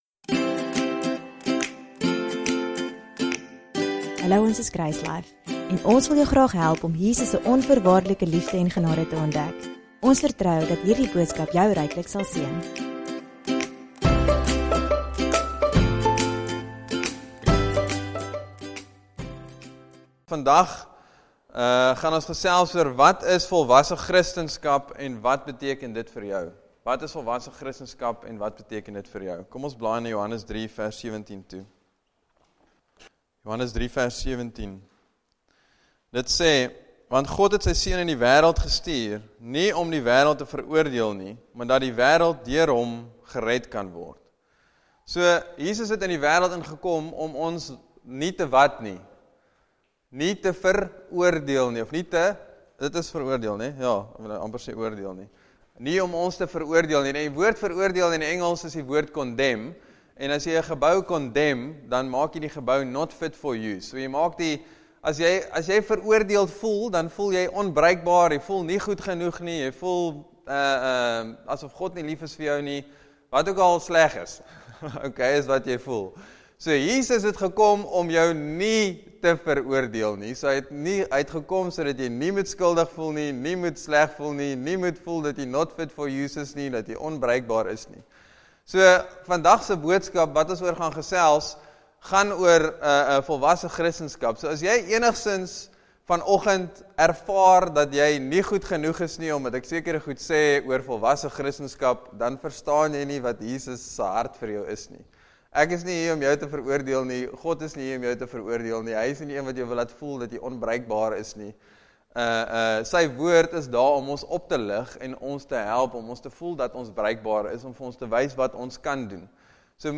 Speaker